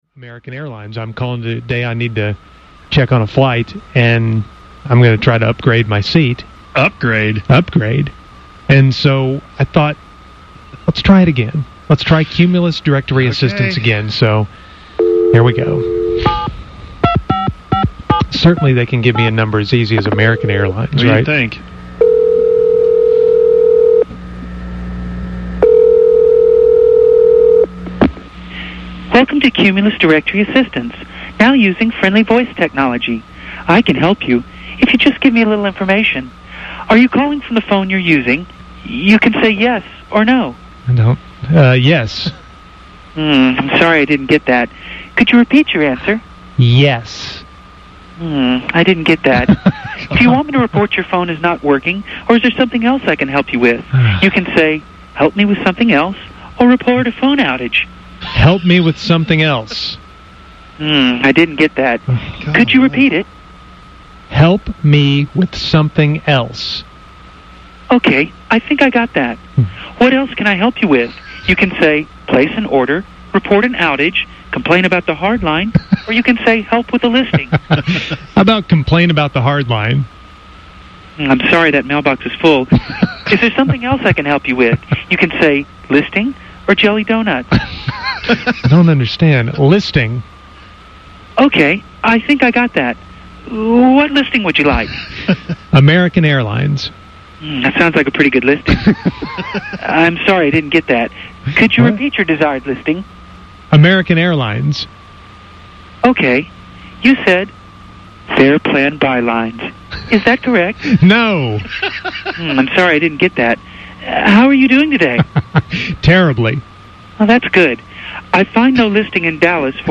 cumulus-phone-system.mp3